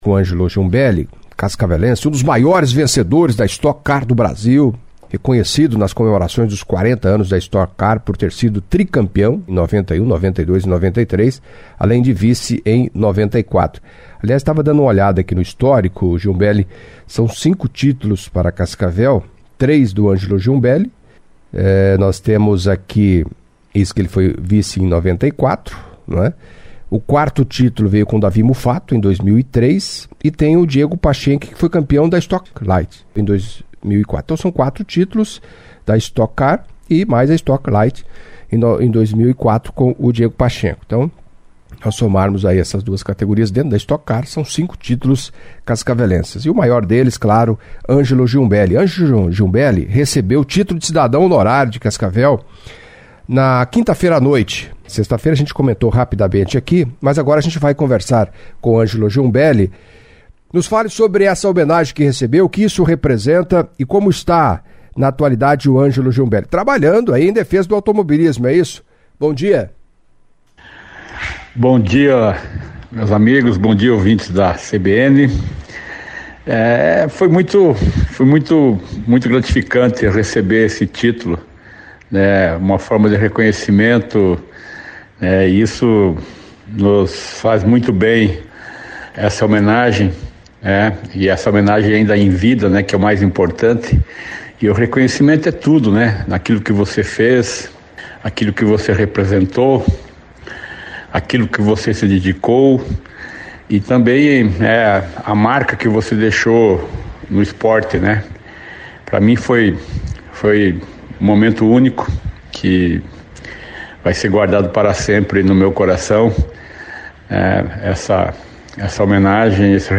Em entrevista à CBN